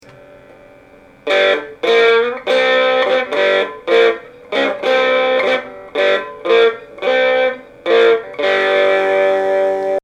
Here is my fender telecaster.